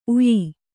♪ uyi